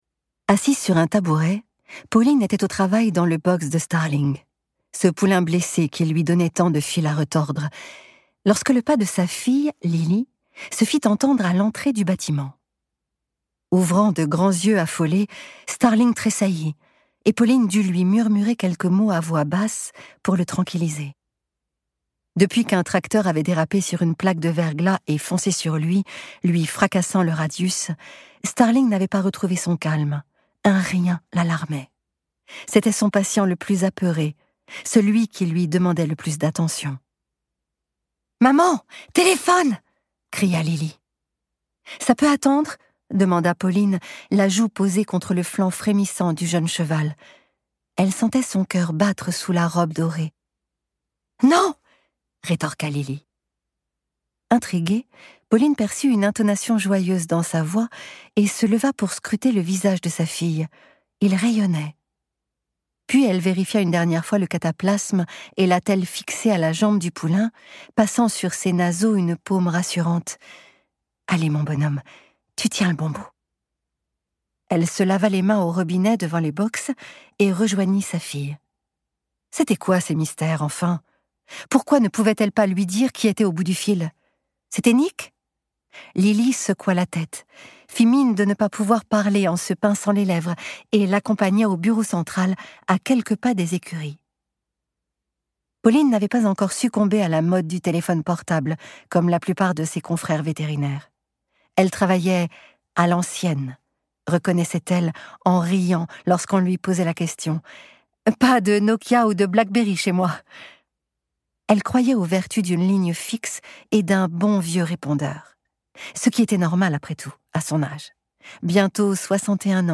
De sa voix douce et chaleureuse, Françoise Cadol nous raconte cette parenthèse dans la vie de Pauline.